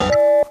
UIBeep_No Access.wav